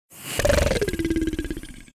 snake-growl.ogg